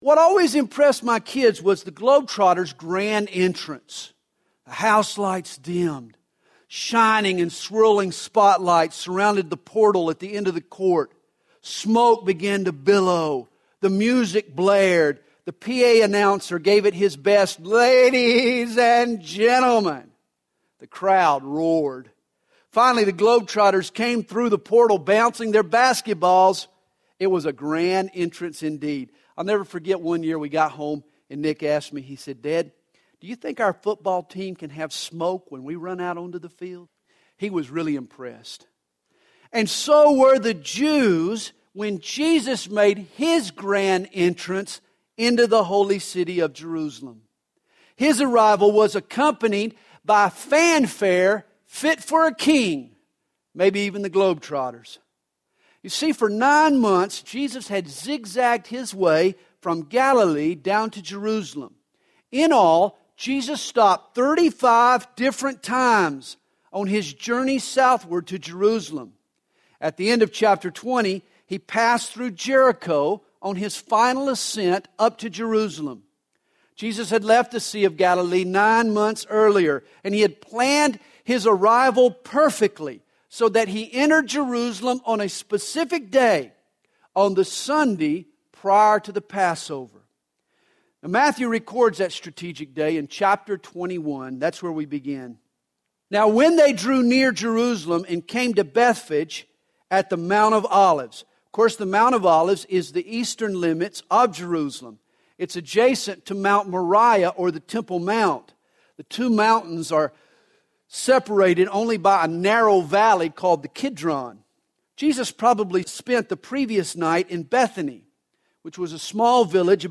Sermon navigation